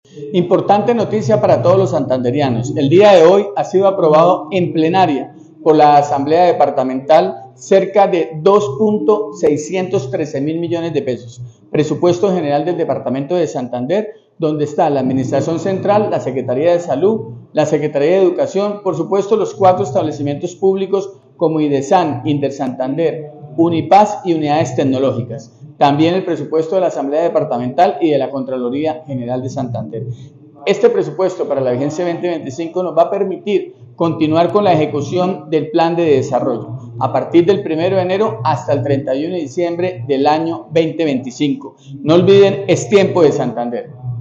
Diego Ariza, Secretario de Santander